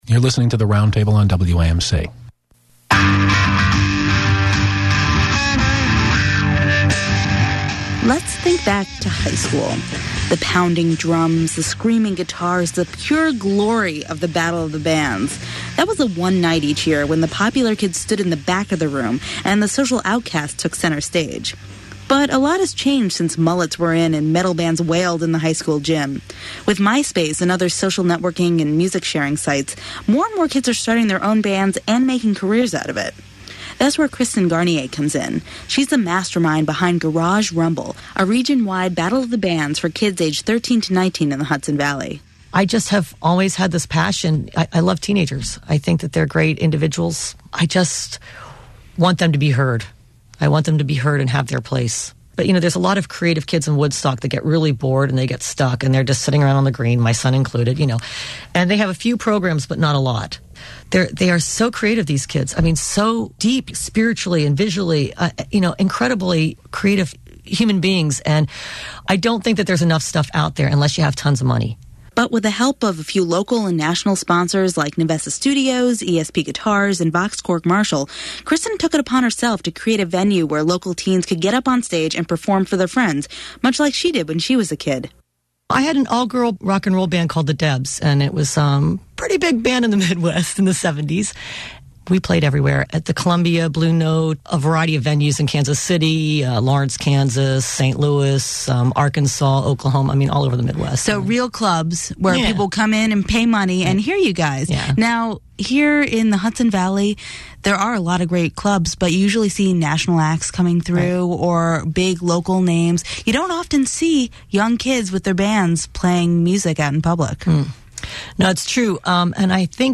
To listen to our interview on WAMC Northeast Public Radio as broadcast on 10.2.06 click here